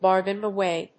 アクセントbárgain awáy